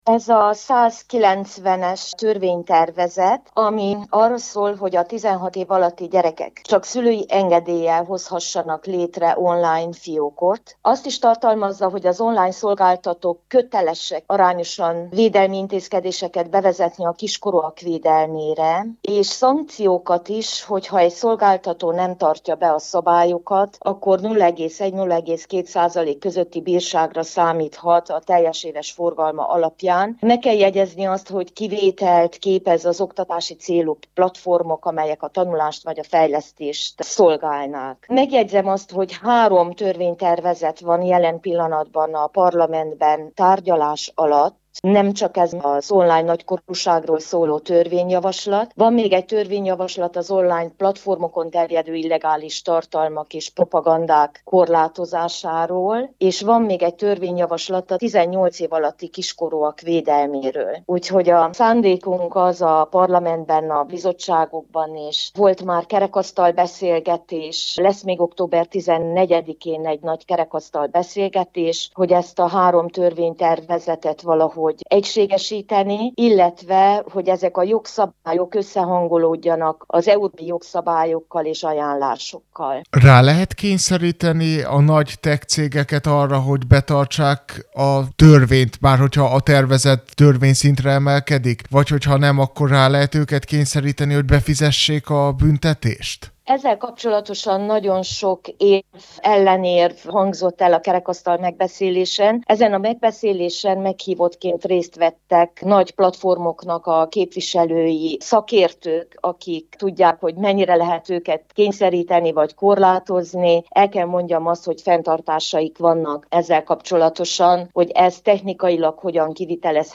A tervezet kapcsán beszélgettünk Kondor Ágota szenátorral, aki a képzés fontosságát is kiemelte.